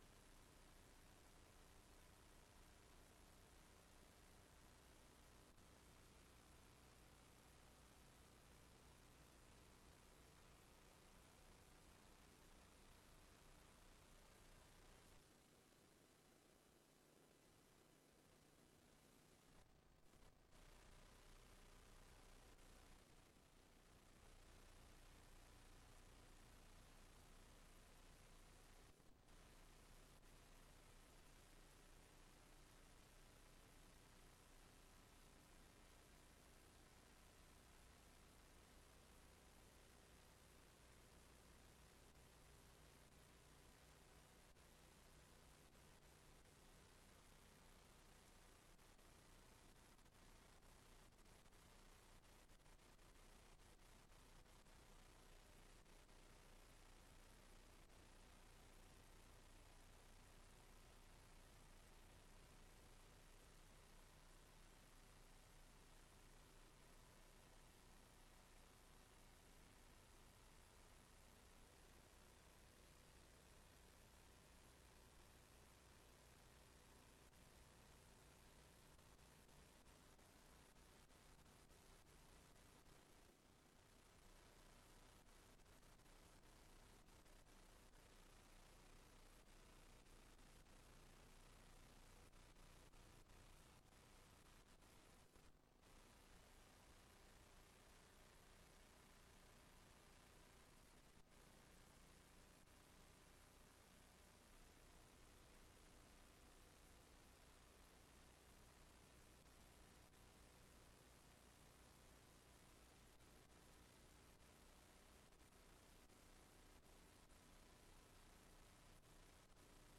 Raadsbijeenkomst 25 juni 2025 19:30:00, Gemeente Tynaarlo
Locatie: Raadszaal